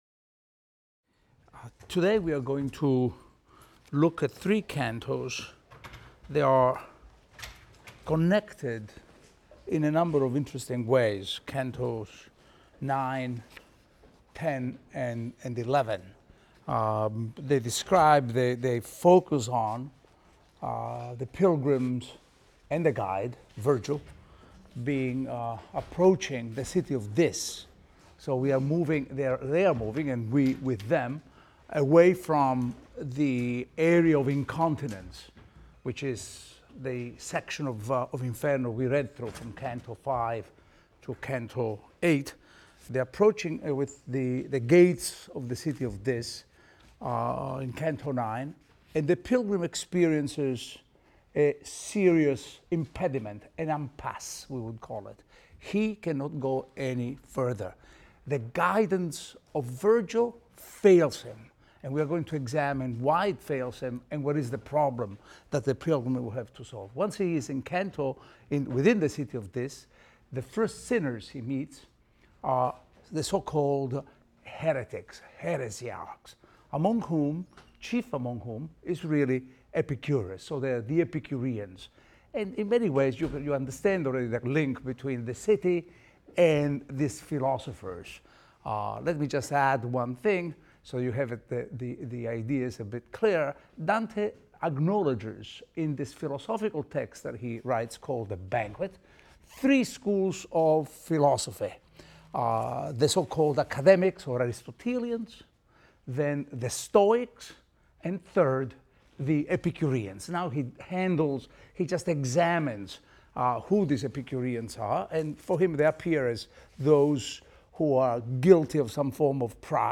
ITAL 310 - Lecture 5 - Inferno IX, X, XI | Open Yale Courses